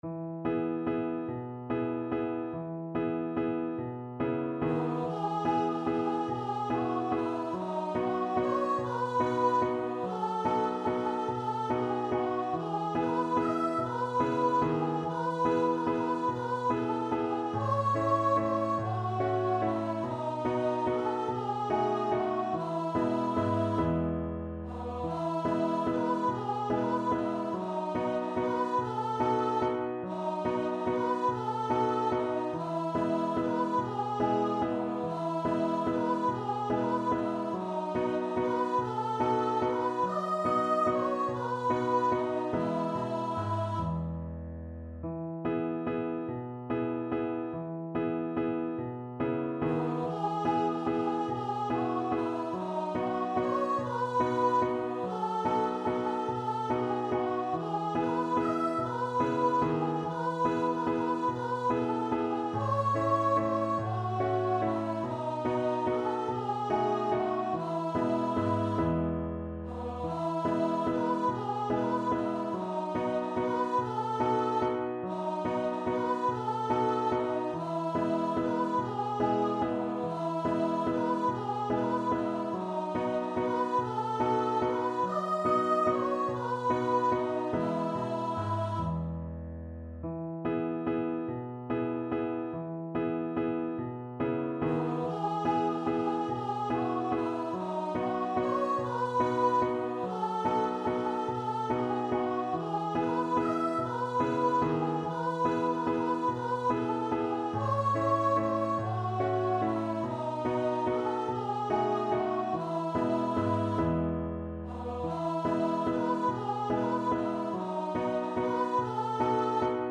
3/8 (View more 3/8 Music)
Steady one in a bar .=c.48
Traditional (View more Traditional Voice Music)